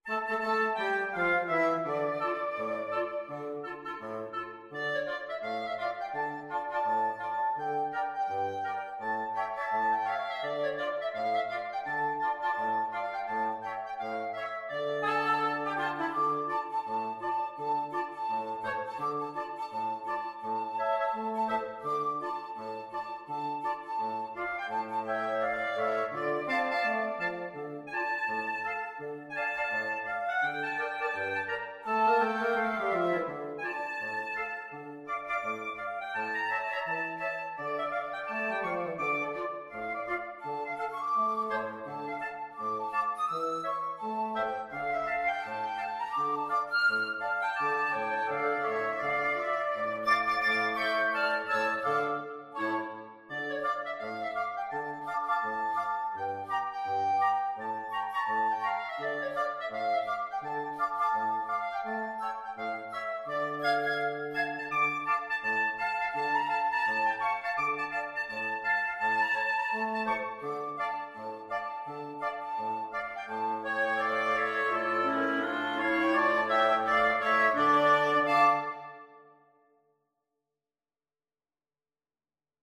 Wind Quartet version
FluteOboeClarinetBassoon
is a well-known African-American spiritual.
2/2 (View more 2/2 Music)
Quick Swing = 84